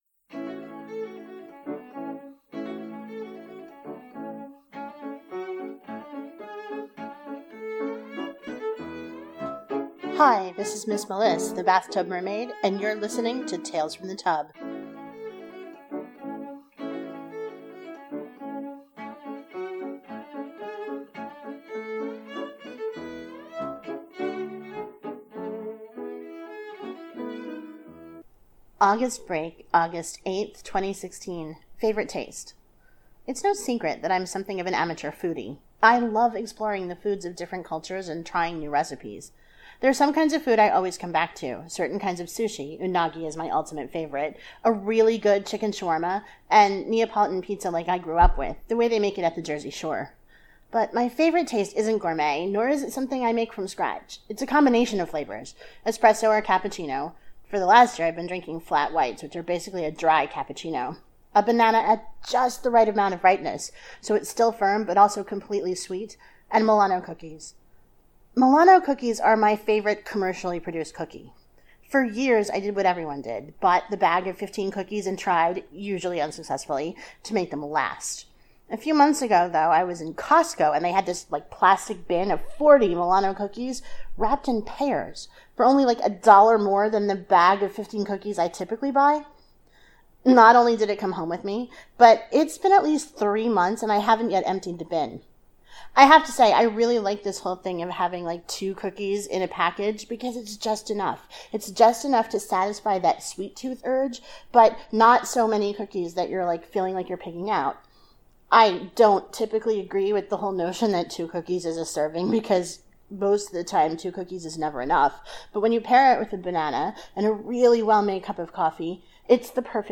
• Music used for the opening and closing is David Popper’s “Village Song” as performed by Cello Journey.